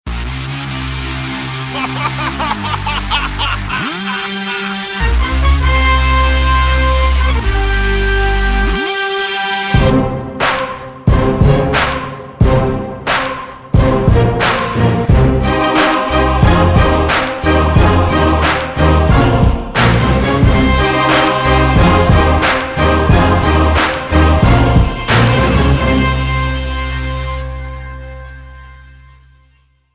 Thể loại nhạc chuông: Nhạc DJ